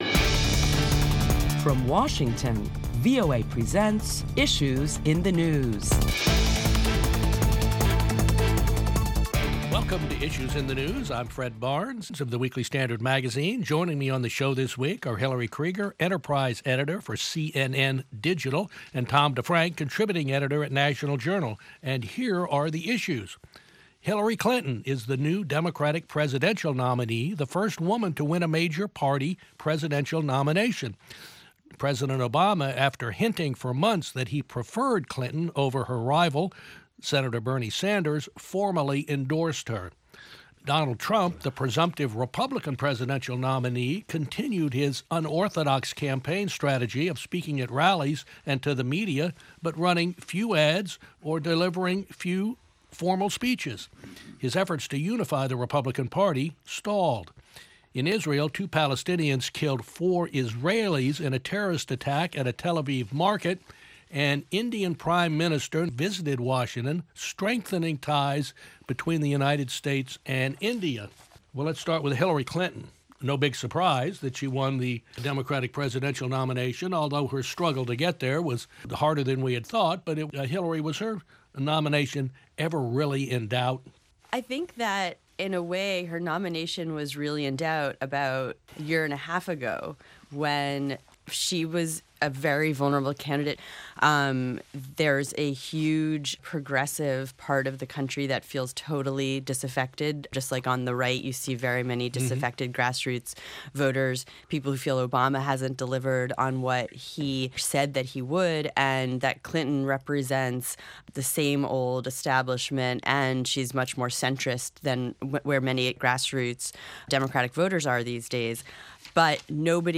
Moderator Fred Barnes